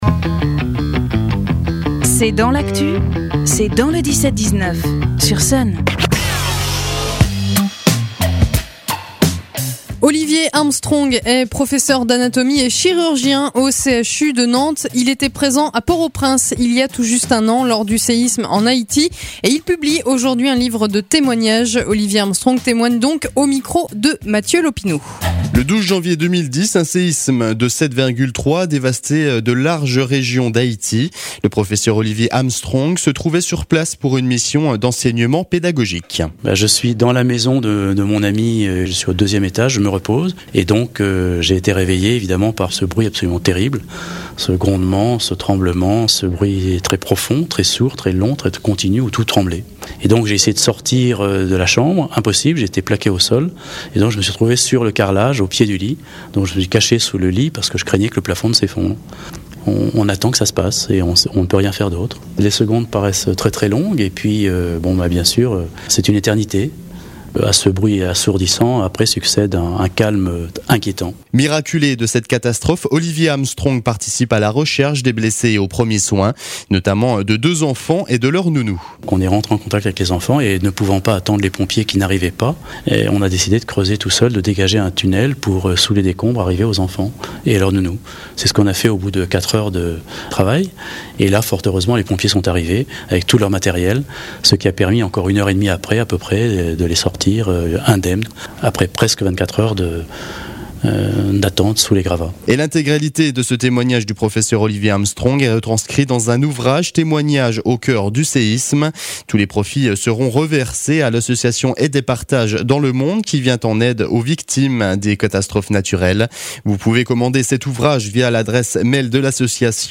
Interview
itw_amitie_vendee_haiti.mp3